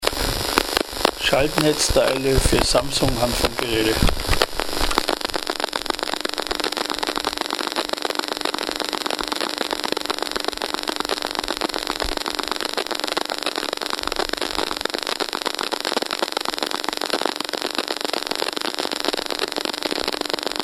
STÖRQUELLEN AUDIODATENBANK
Schaltnetzteil / Ladegerät Samsung 1A Low E-Field Netz belastet/ladend 100-149